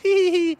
Play Giggle Hehehe - SoundBoardGuy
Play, download and share Giggle hehehe original sound button!!!!
hehehe_wKz67Zm.mp3